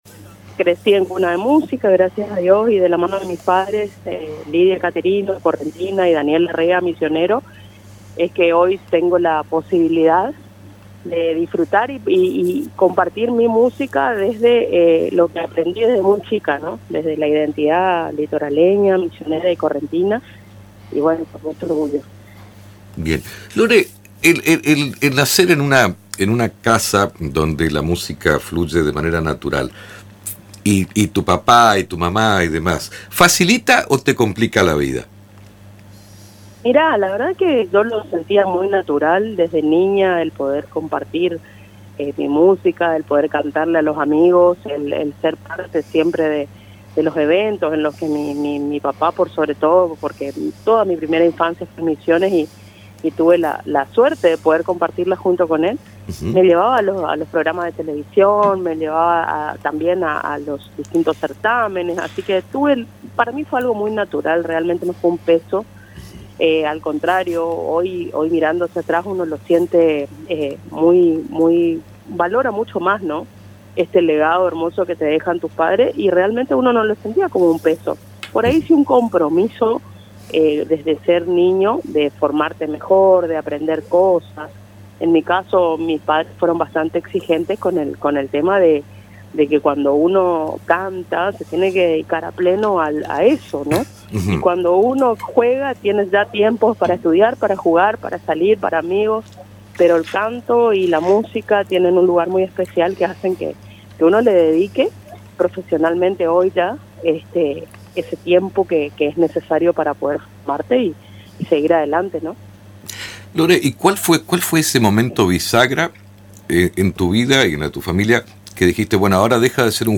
una entrevista exclusiva con Radio Tupambaé